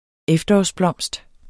Udtale [ ˈεfdʌɒs- ]